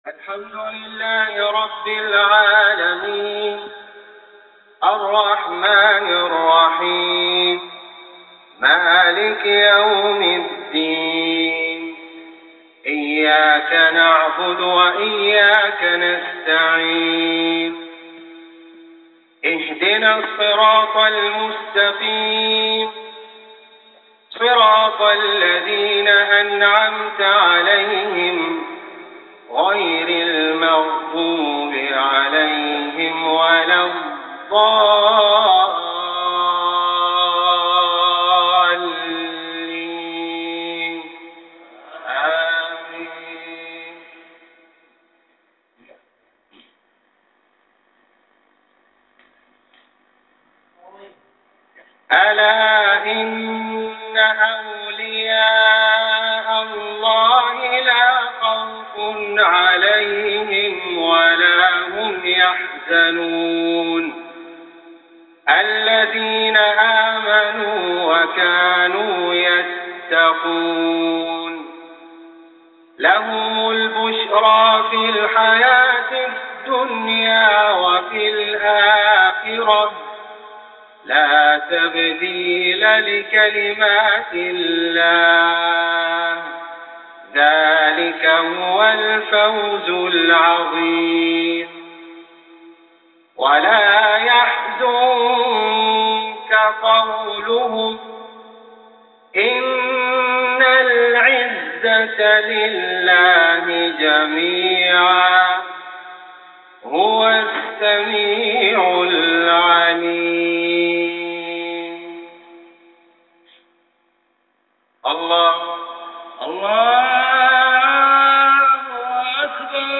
تلاوة لإمام الحرم الجديد
سجلت الركعة الثانية من صلاة العشاء اليوم لإمام الحرم الجديد لم أتمكن من تسجيل الركعة الأولى كاملة
** مضاف إليها صدى ،،
ماشاء الله صوت رائع جداً